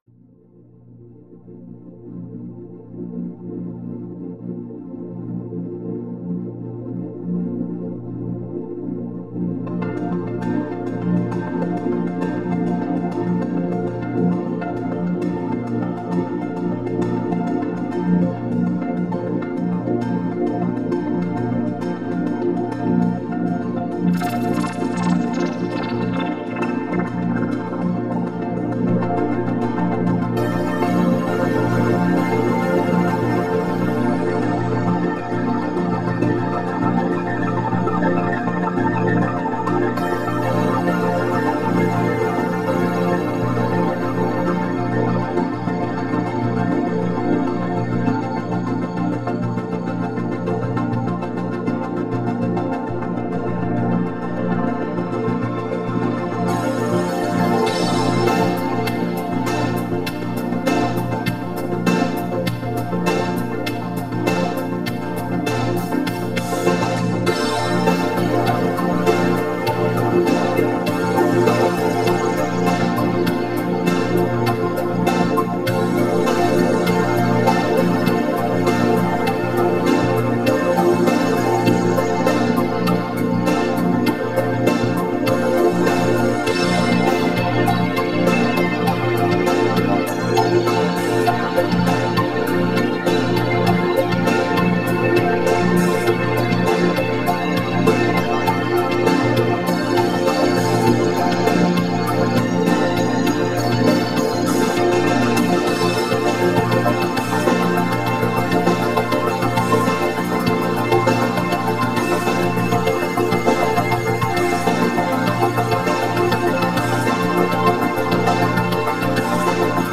fmaj vocoder.mp3